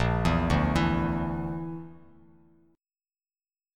A#Mb5 chord